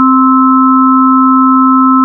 Middle C - 261.63 Hz, complex tone, fund. + 4th & 5th partials
The pitch sensation for all 3 of these sounds should be that of middle C. Even though the last sound does not even contain the frequency associated with middle C (261.63 Hz) the sensation should still be that of middle C. The 4th partial (harmonic) is a sine wave at 4 x 261.63 Hz = 1,046.5 Hz.